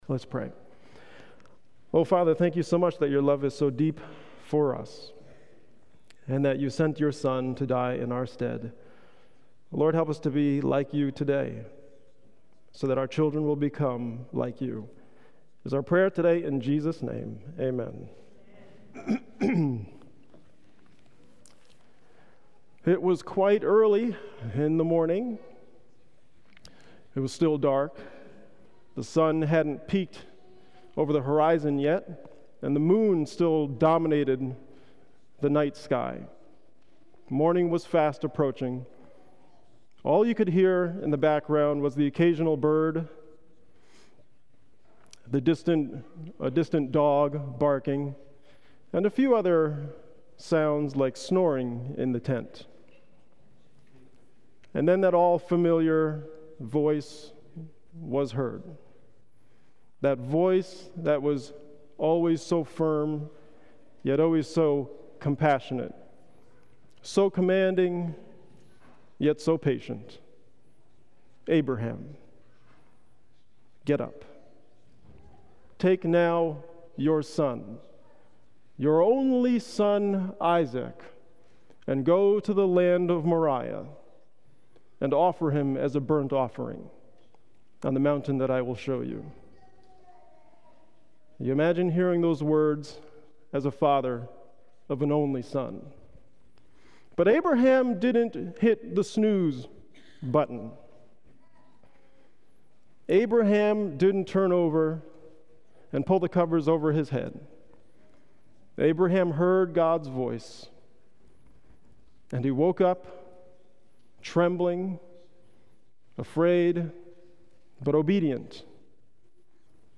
Sabbath Sermons